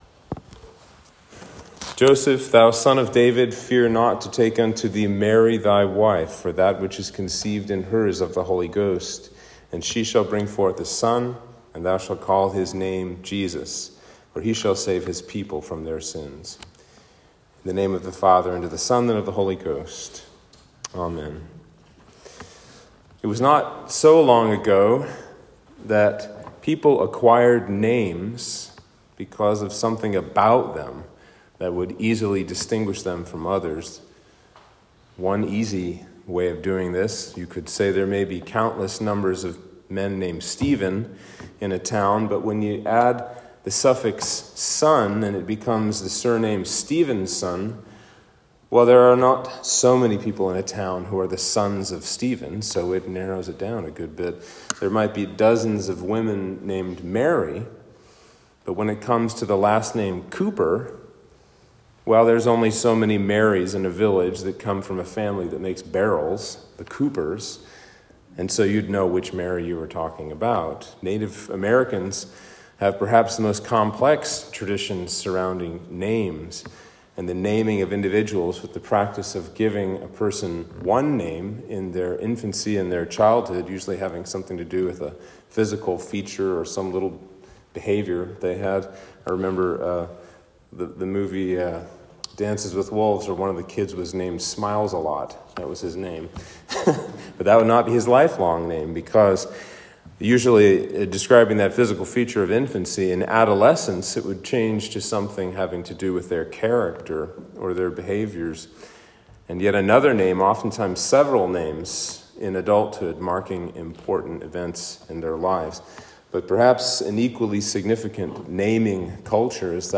Sermon for Christmas 1